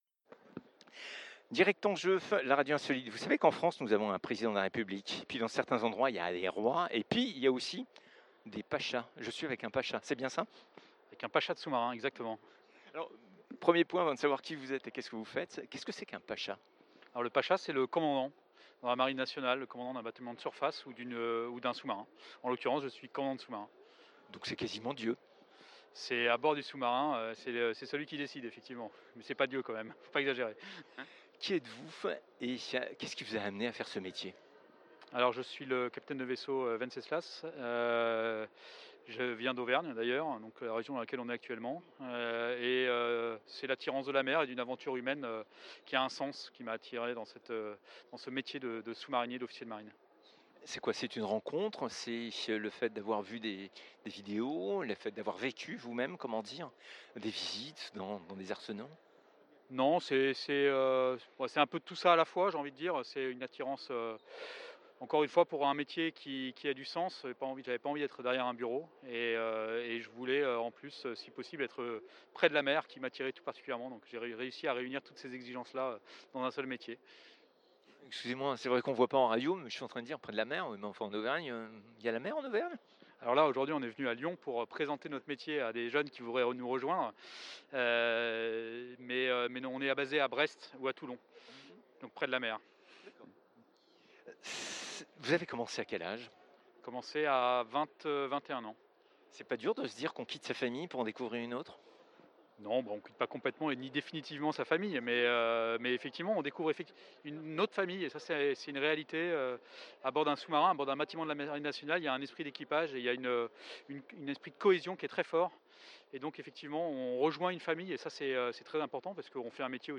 Emission Spéciale Forum des Métiers Marine Nationale à l'Hotel de Région Auvergne Rhone Alpes